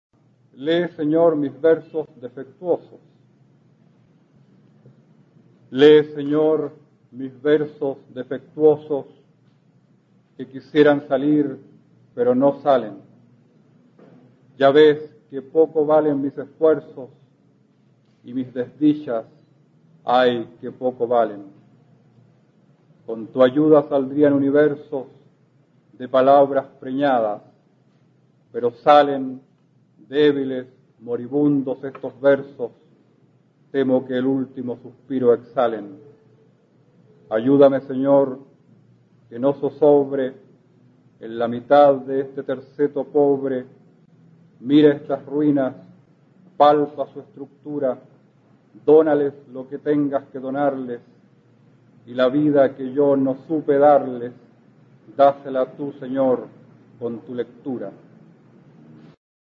A continuación se puede escuchar al poeta chileno Óscar Hahn, perteneciente a la Generación del 60, recitando su poema Lee, Señor, mis versos defectuosos, donde demuestra toda su habilidad para componer sonetos. Pertenece al libro "Estrellas fijas en un cielo blanco" (1889).